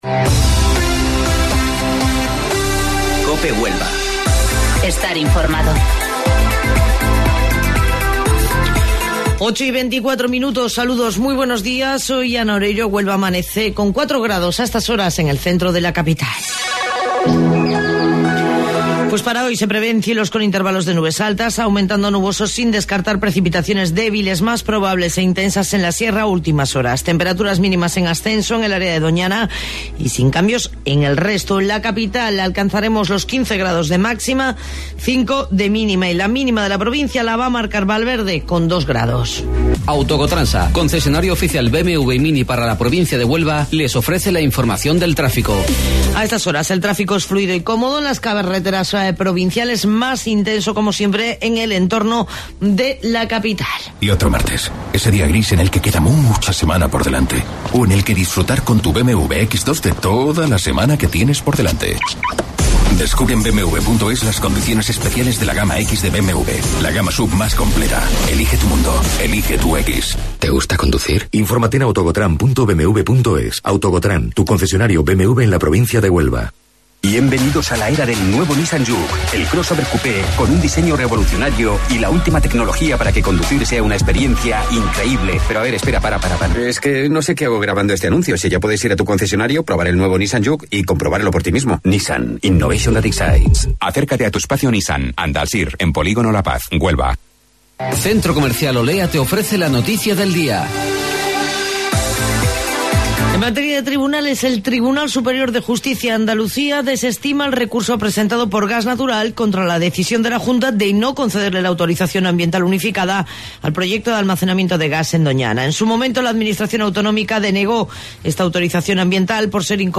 AUDIO: Informativo Local 08:25 del 14 de Enero